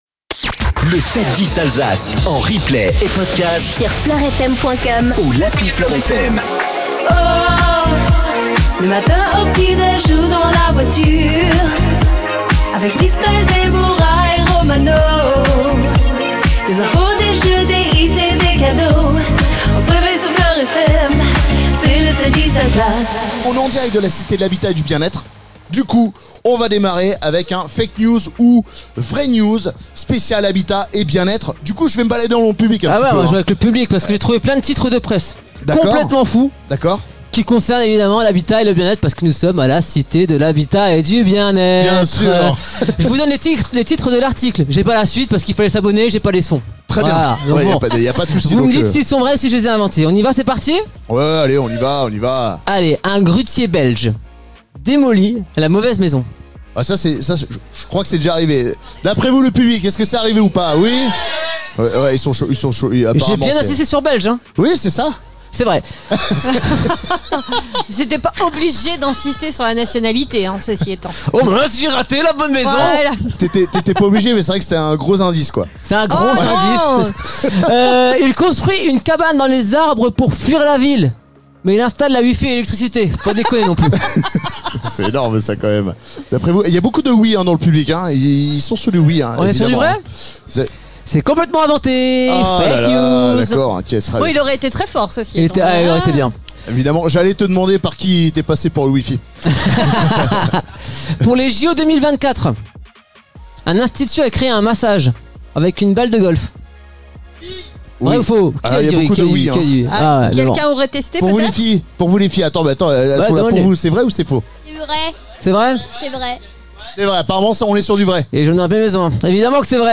en direct de la Cité de l'Habitat & du Bien-Être